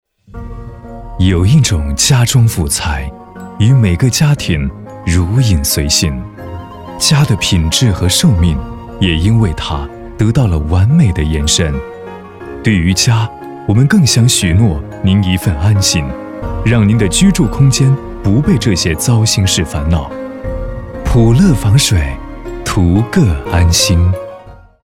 标签： 厚重
男国347_宣传片_企业_防水公司_温情.mp3